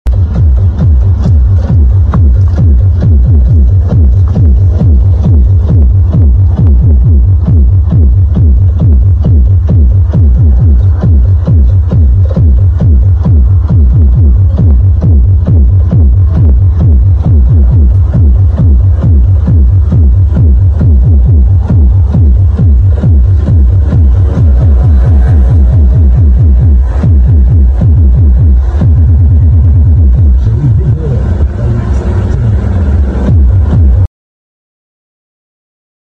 Mohwk Double Subwoofer Di Kereta sound effects free download